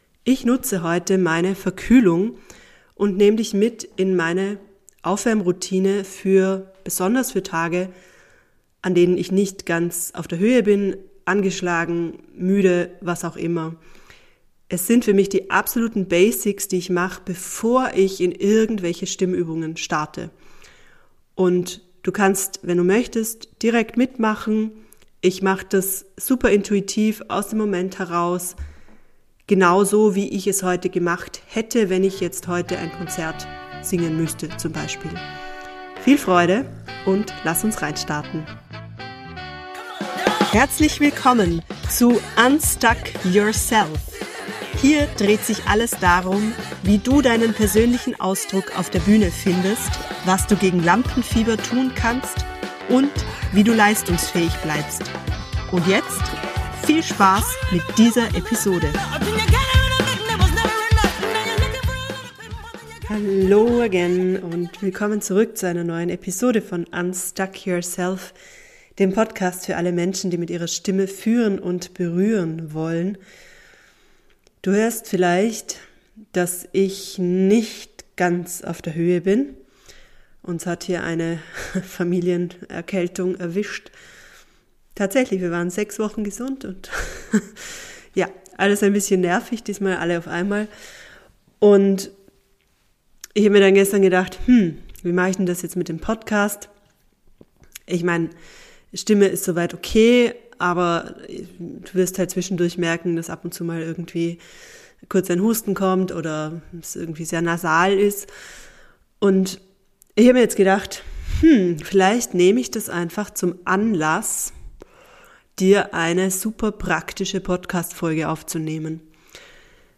Beschreibung vor 2 Monaten Ich bin erkältet und nehme dich in dieser Episode mit in meinen Aufwärmprozess an solchen Tagen.
Denn diese 15 Minuten nehmen Druck raus und machen einen hörbaren Unterschied. Dieses Warm-up ist spontan und live - direkt für dich zum Mitmachen. Inklusive Vorher-Nachher-Hörprobe.